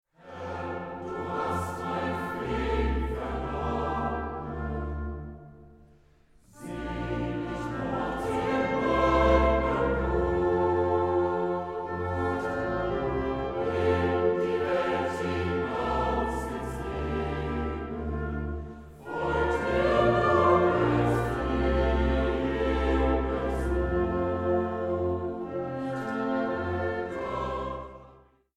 Chor, Orchester